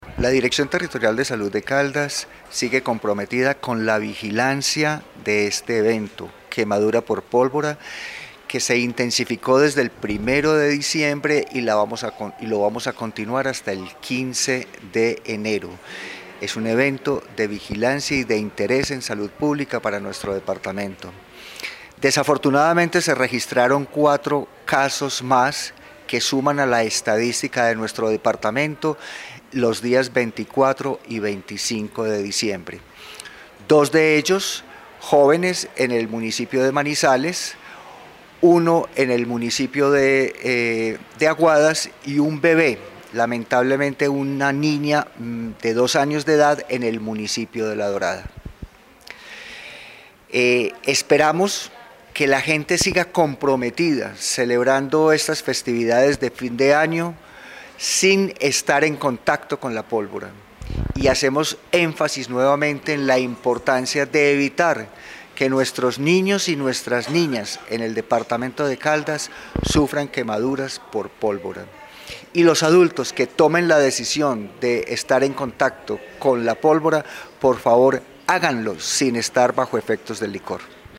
Jorge Rubio Jiménez, subdirector de Salud Pública de la DTSC.